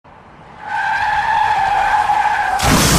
Free Horror sound effect: Shattering Mirror.
Shattering Mirror
441_shattering_mirror.mp3